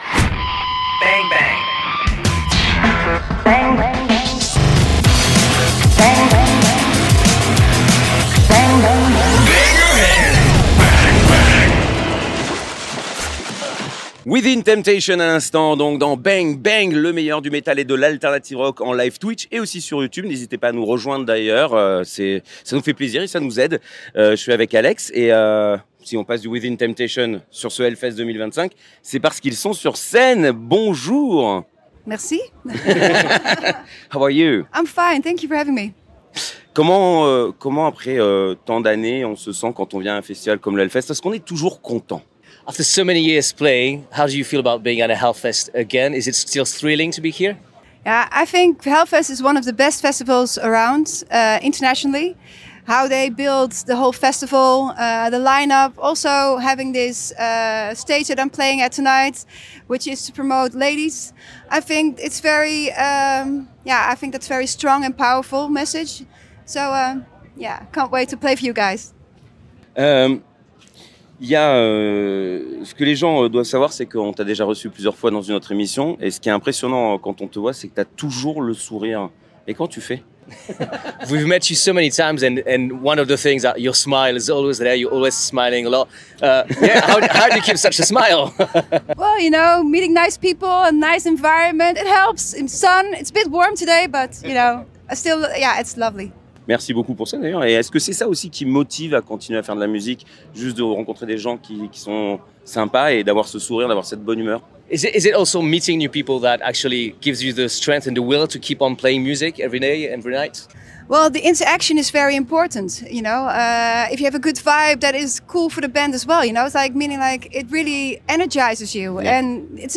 Et nous avons fait 23 interviews !